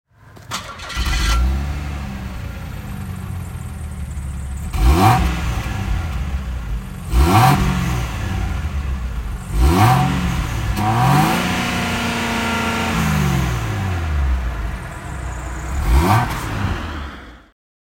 Ford Probe 2.5 GT V6 (1993) - Starten und Leerlauf
Ford_Probe_GT_1993.mp3